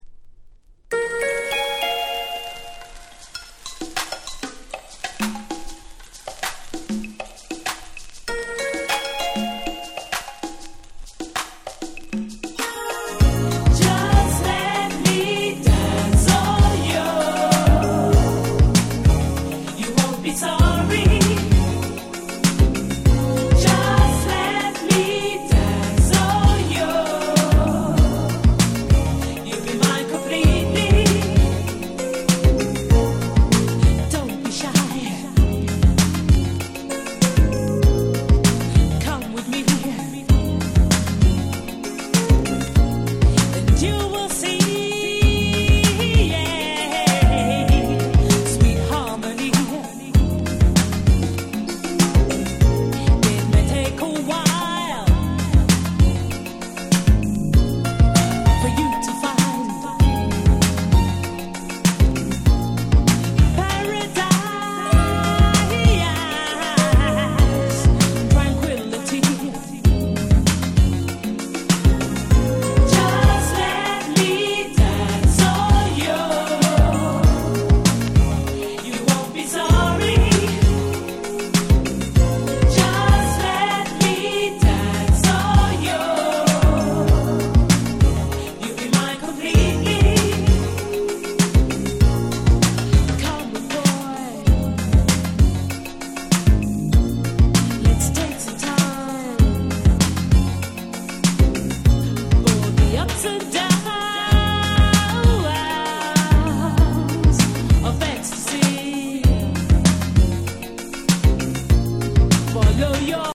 89' Super Nice UK Street Soul / R&B !!
ブラコン感覚で聴けちゃうめちゃ良い女性Vocal物です！